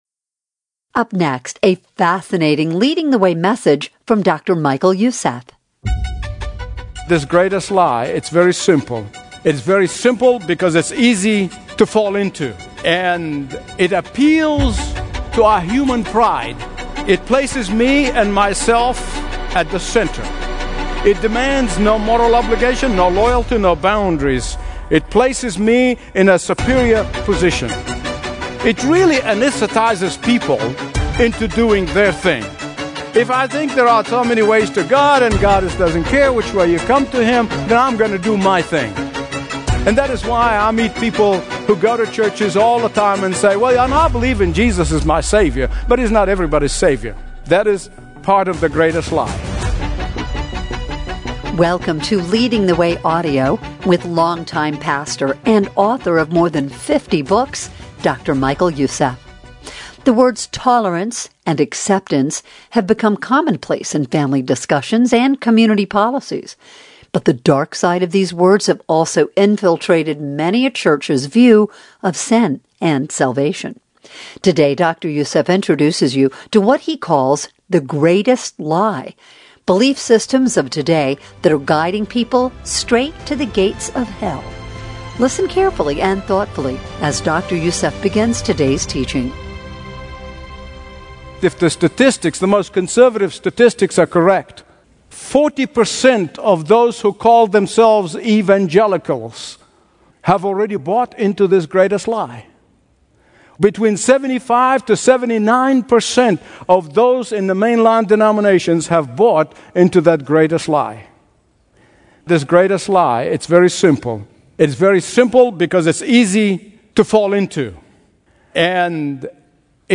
Listen to Dr. Michael Youssef's Daily Teaching on The Beginning of It All in HD Audio.